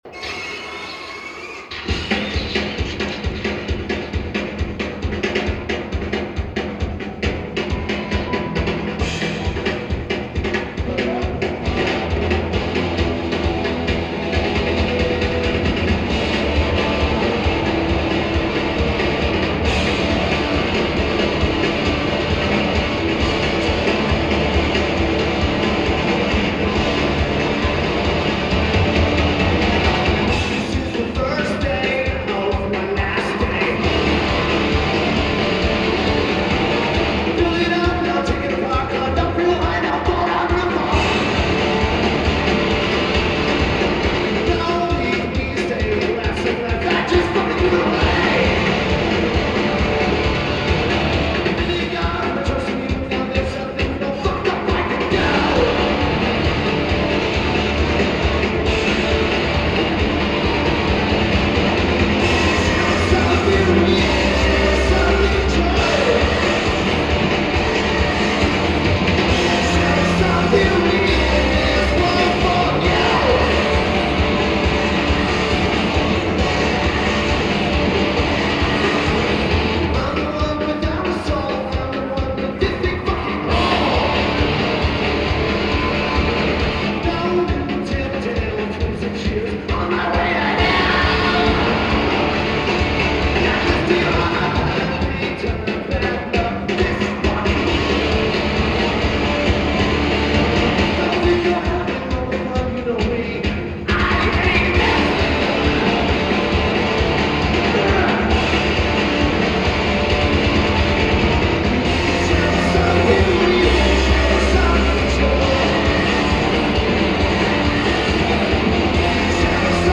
Great Woods Arts Center
Drums
Guitar
Lineage: Audio - AUD (Unknown Mic + Sony WM-D6)